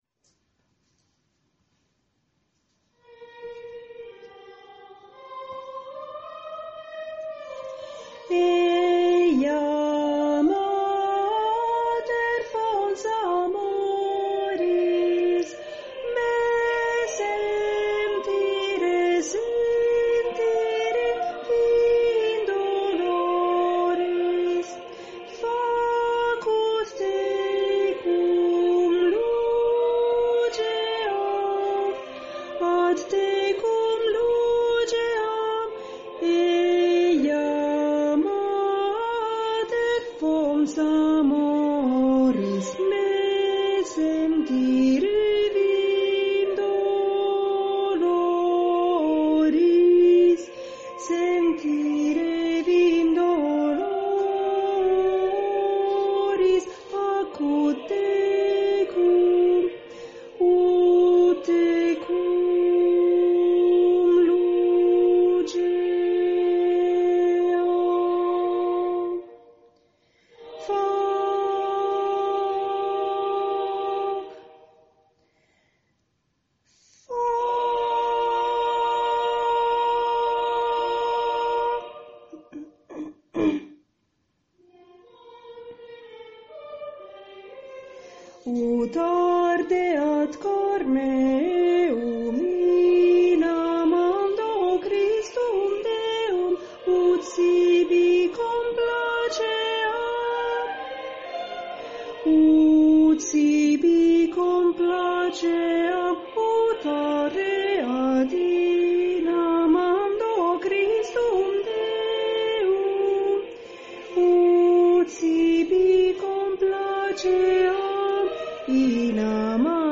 Stabat Mater - Contralti_coro 1_parte cantata